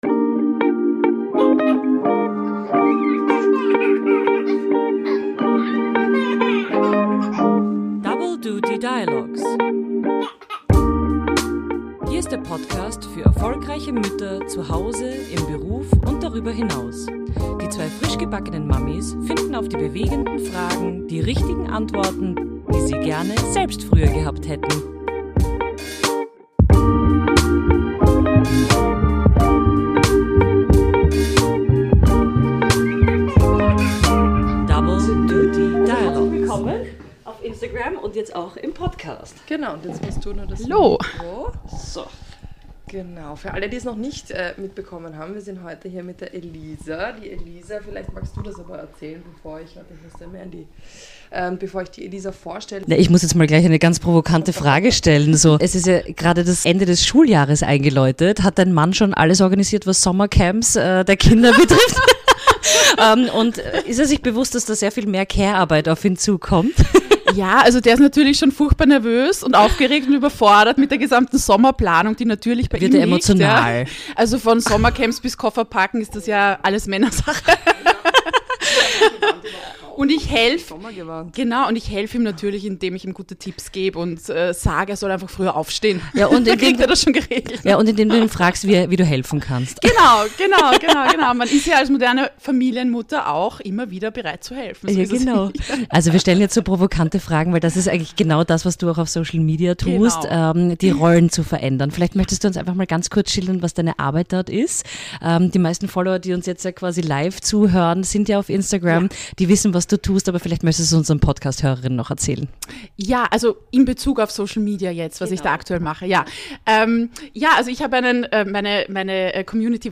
Freut euch auf ein Gespräch über Feminismus, Karriere, Führungsstärke, Elternschaft und den Mut, gesellschaftliche Normen zu hinterfragen und anzuerkennen, dass Frauen generell extreme Mehrarbeit leisten müssen - in allen Branchen und zu Hause.